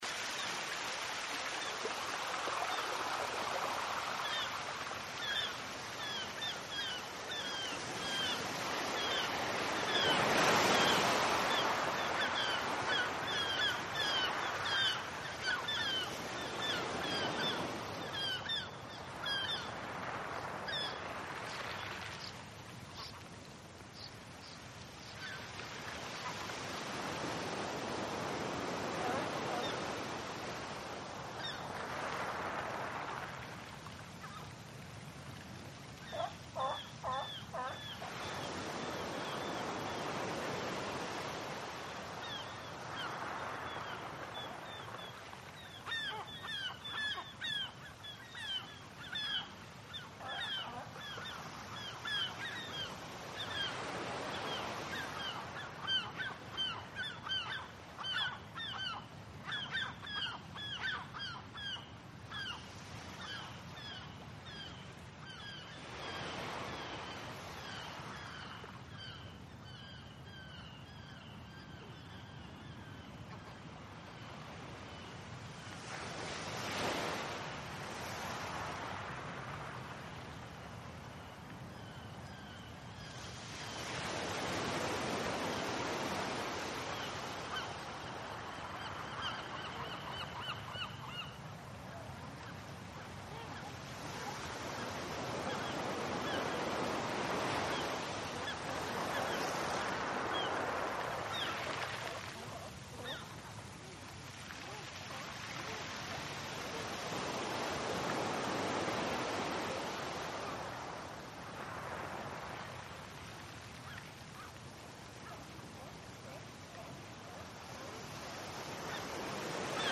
Звуки морского прибоя
На этой странице собраны звуки морского прибоя в высоком качестве: от легкого шелеста волн до мощного грохота океана.